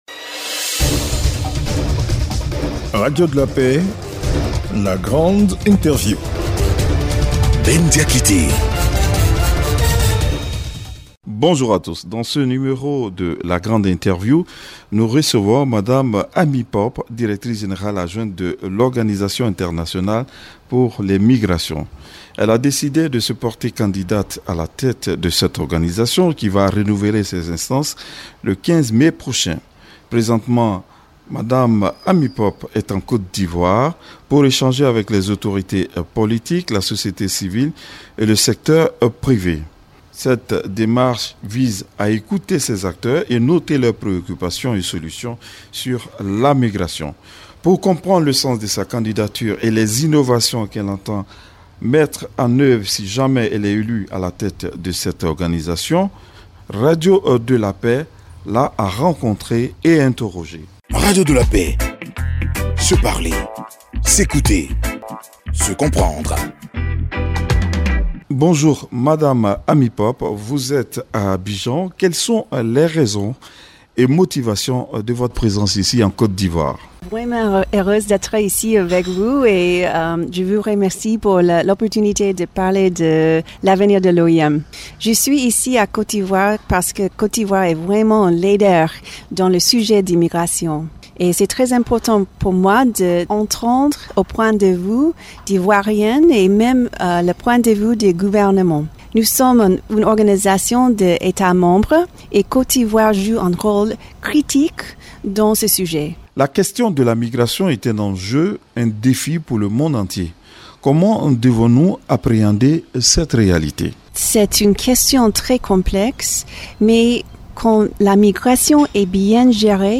La Grande Interview du 14 janvier 2023 - Site Officiel de Radio de la Paix
C’est un immense plaisir que j’ai interviewé Amy Pope directrice adjointe de l’OIM en visite en Côte d’Ivoire. elle est venue écouter les Ivoiriens sur les migrations.